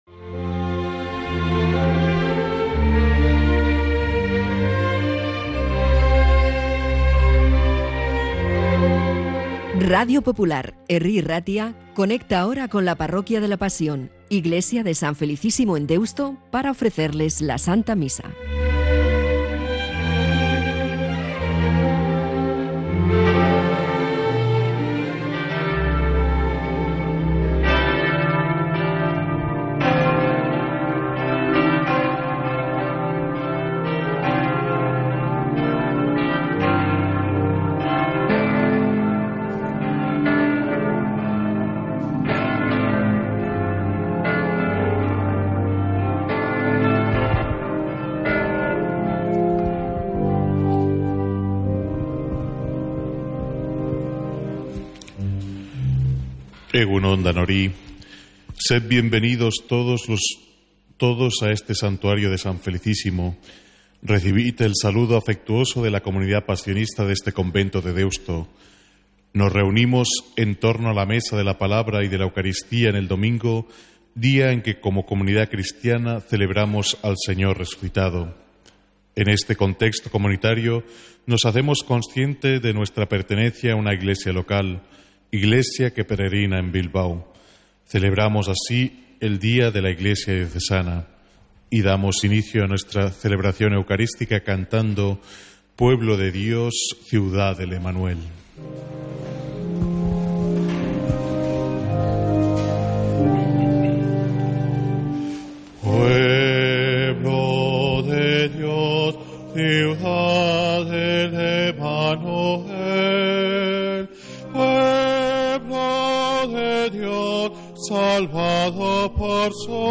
Santa Misa desde San Felicísimo en Deusto, domingo 10 de noviembre